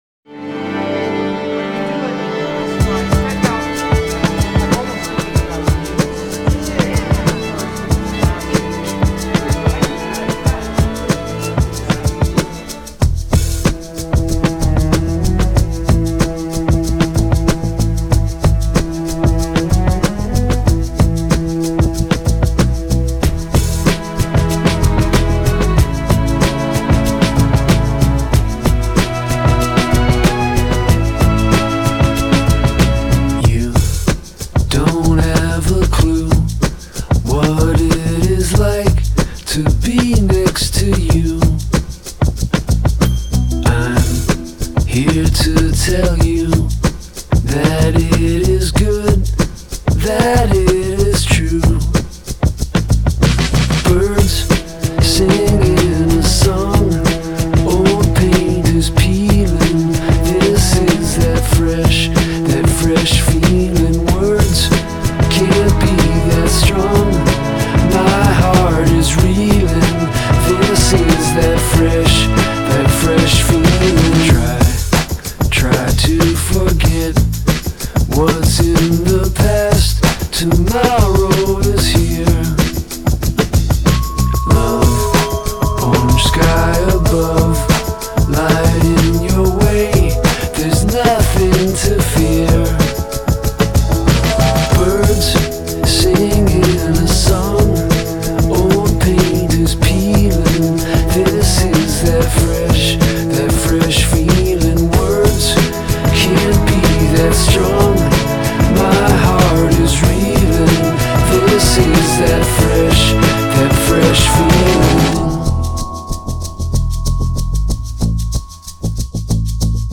Alternative rock Indie rock Indie